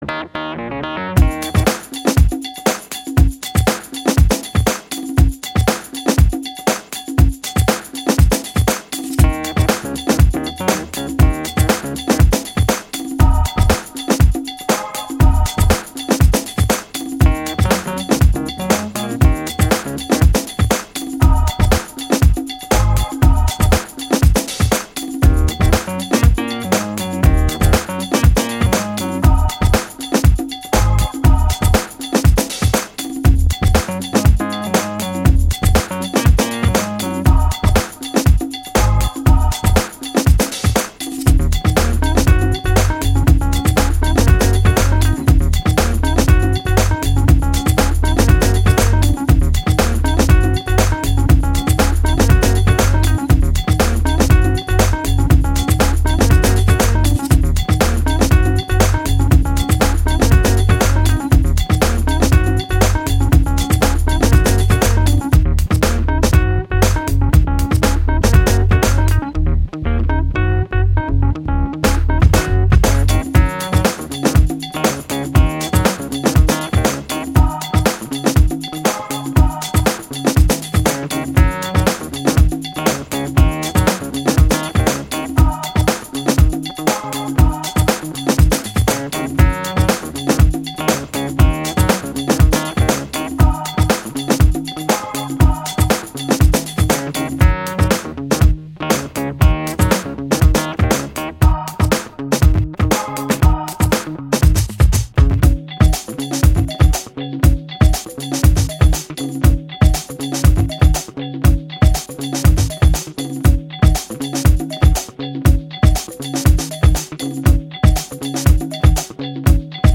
Kalimba.mp3